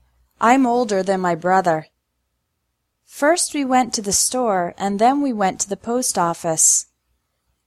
Pronunciation of Than and Then
In spoken English, these words often sound exactly the same!